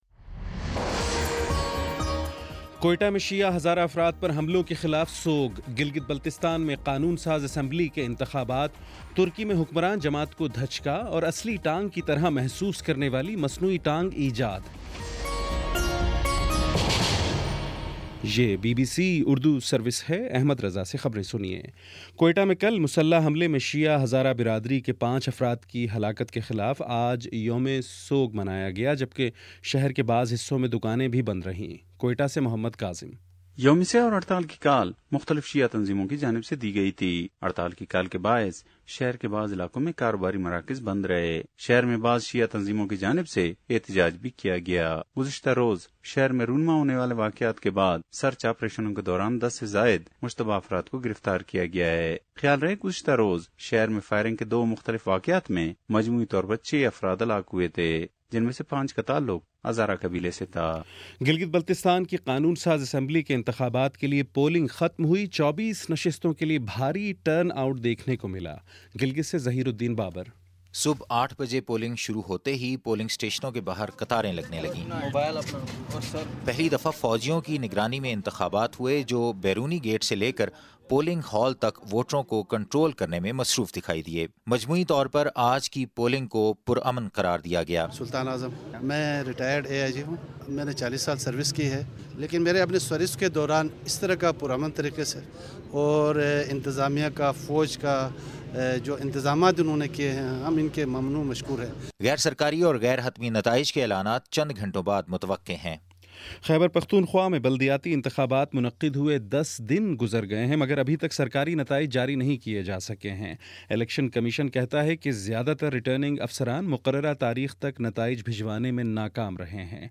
جون 8: شام پانچ بجے کا نیوز بُلیٹن